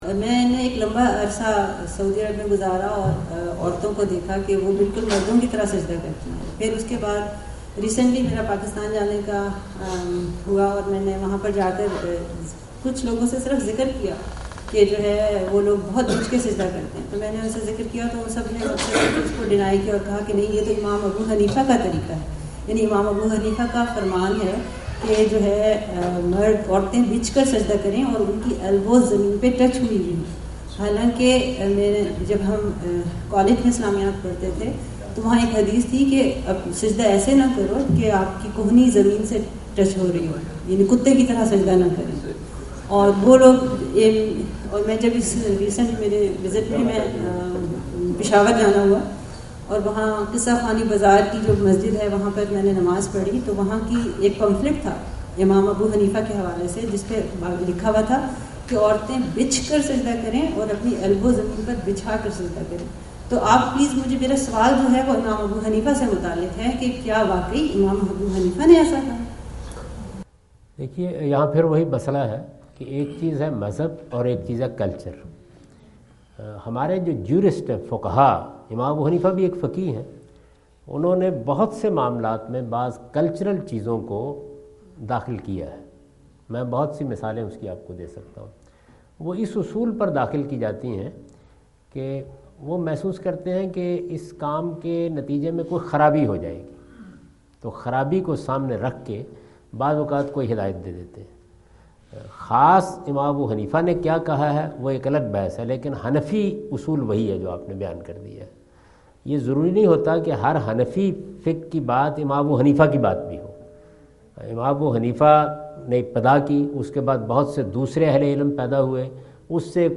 Javed Ahmad Ghamidi answer the question about "View of Imam Abu Hanifa about Women’s Prostration" During his US visit in Dallas on October 08,2017.